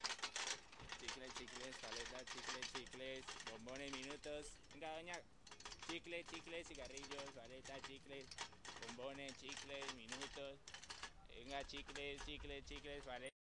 哥伦比亚 " 街道上安静的早晨，小城镇或城市的远处有轻快的交通和鸟类，男人走过时在附近唱歌，狗在追赶。
描述：街道安静的早晨小镇或城市遥远的光交通和鸟类，男子走近唱歌开始，狗追逐街道中间，鸟儿飞走有时蹩脚的翅膀Saravena，哥伦比亚2016
Tag: 西班牙语 街道 上午 宁静